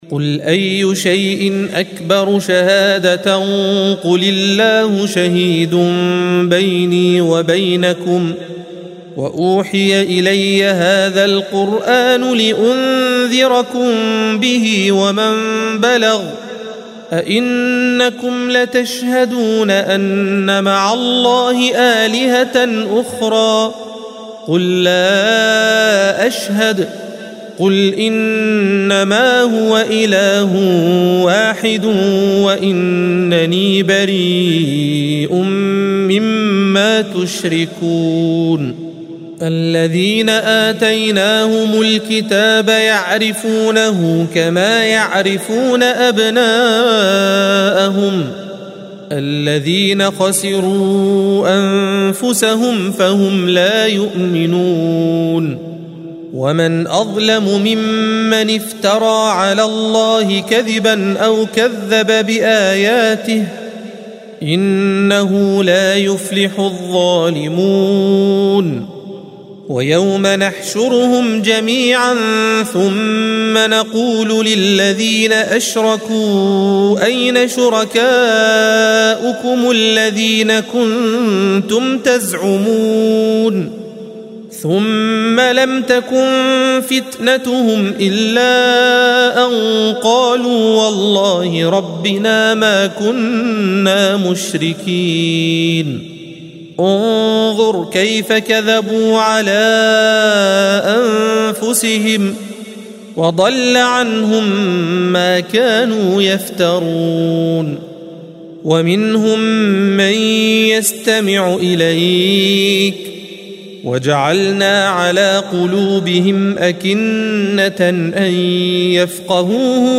الصفحة 130 - القارئ